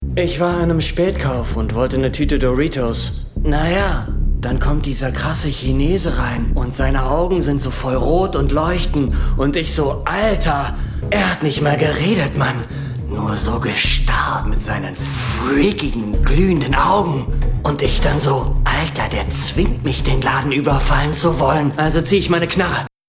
★ Rolle: Stoner